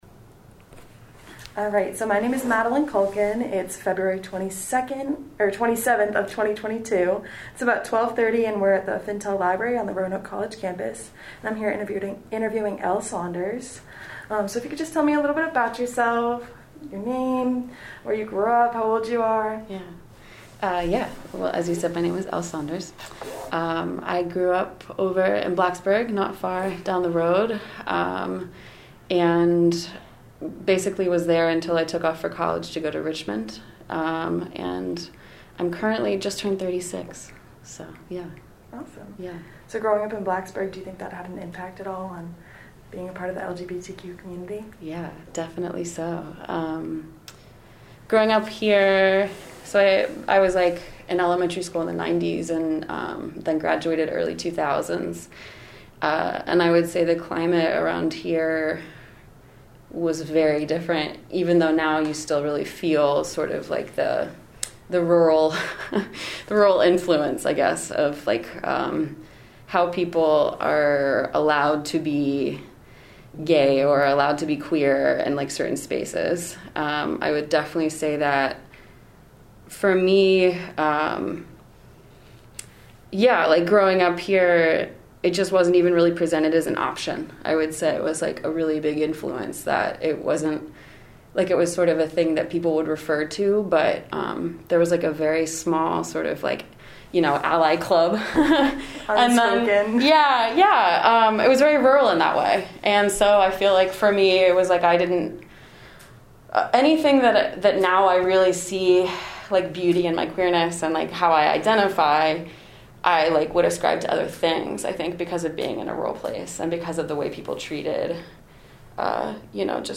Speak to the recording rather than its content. Location: Fintel Library, Roanoke College